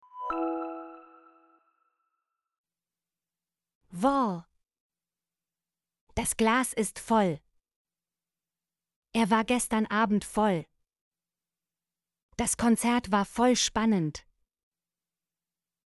voll - Example Sentences & Pronunciation, German Frequency List